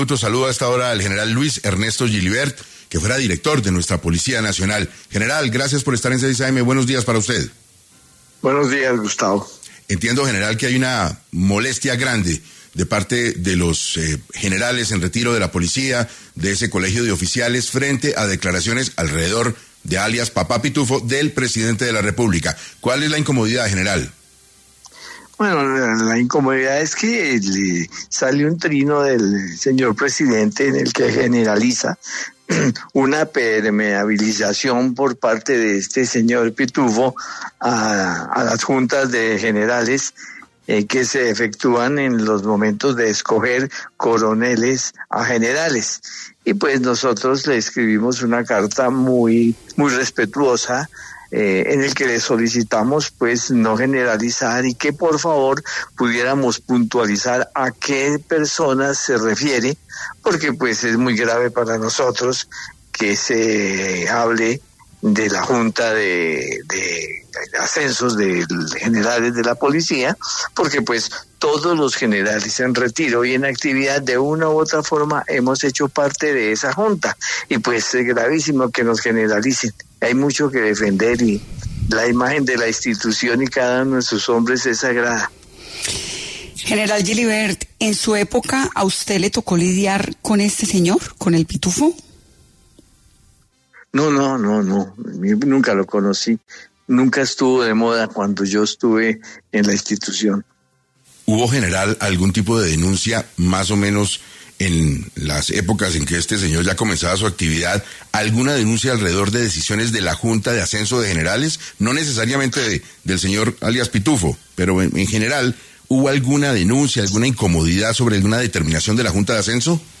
En Caracol Radio estuvo el Gral. Luis Ernesto Gilibert, ex director de la Policía Nacional.